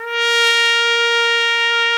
Index of /90_sSampleCDs/Roland L-CDX-03 Disk 2/BRS_Piccolo Tpt/BRS_Picc.Tp 2 St